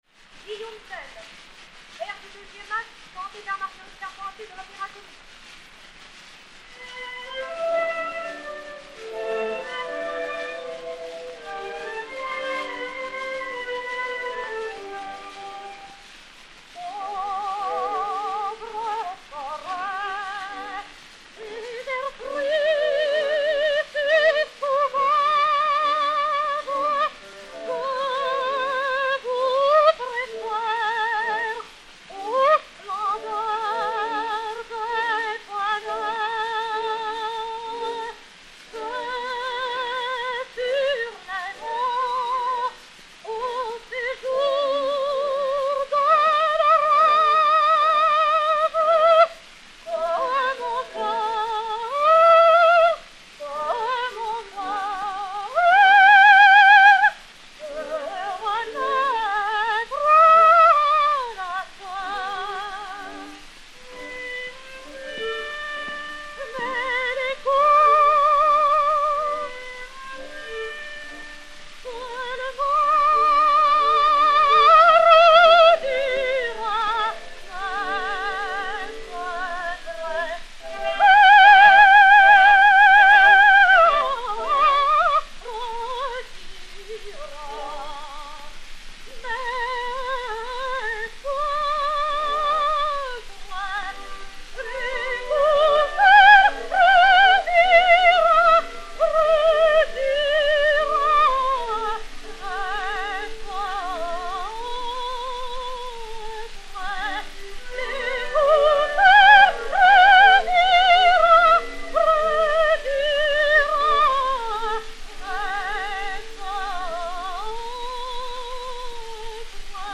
soprano de l'Opéra-Comique Orchestre
et Orchestre
Pathé saphir 90 tours n° 580, réédité sur 80 tours n° 48, enr. en 1911